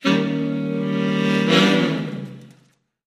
Music Effect; Jazz Saxophone Chords.